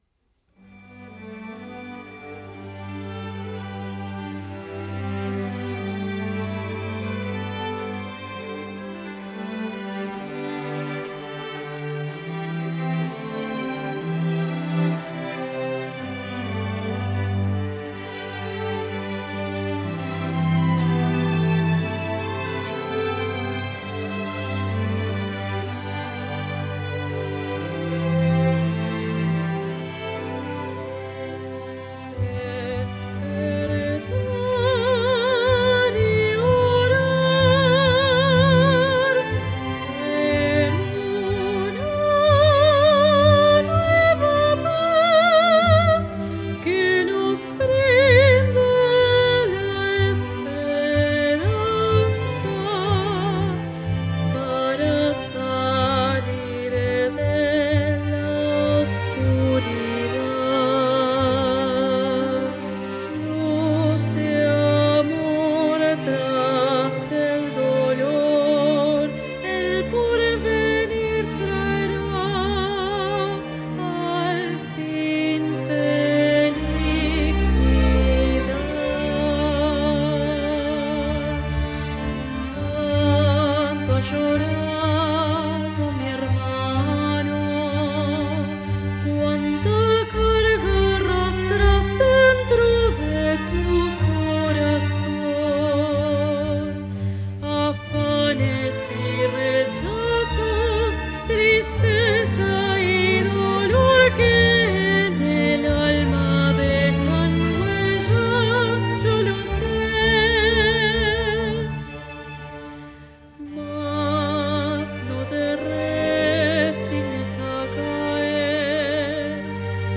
·        Mood: contemplative, lyrical
Strings, Voice soloist (soprano or tenor) - SPANISH
CROSSOVER VERSION: